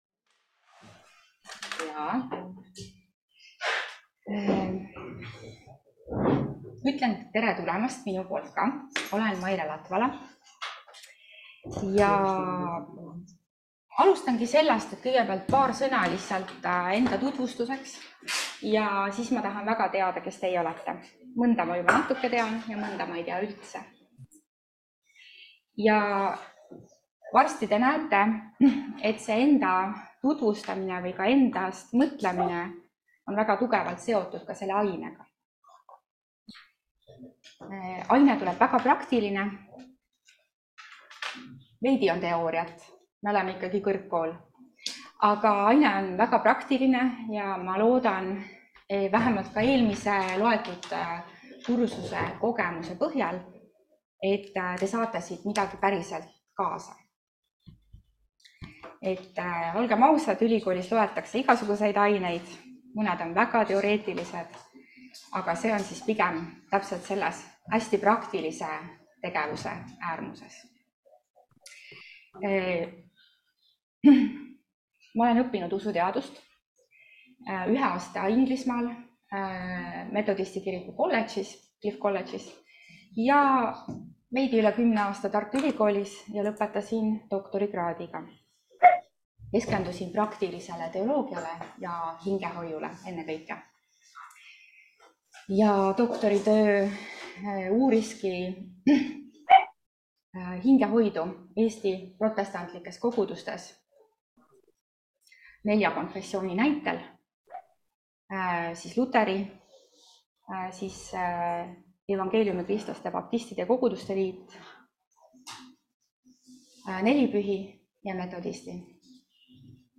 Perenõustamine. 1. loeng [ET] – EMKTS õppevaramu